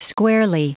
Prononciation du mot squarely en anglais (fichier audio)
Prononciation du mot : squarely